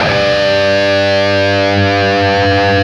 LEAD G 1 CUT.wav